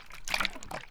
slosh1.wav